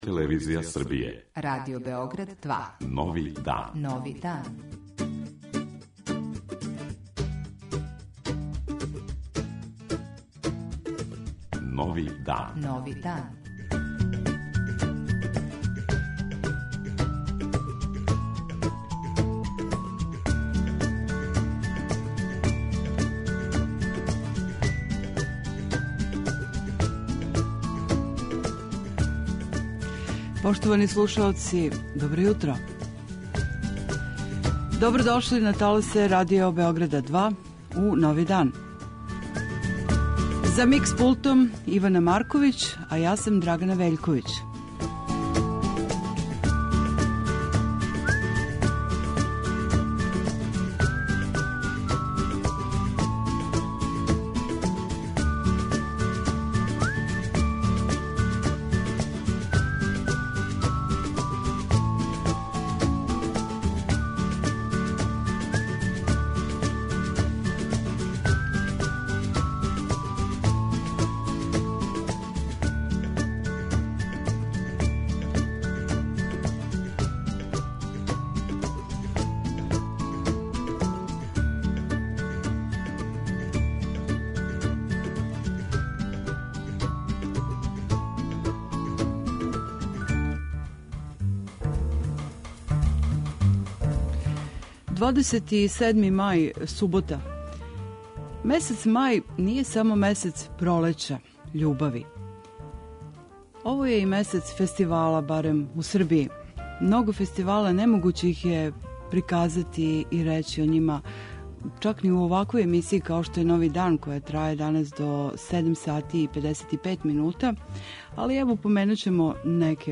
Јутарњи магазин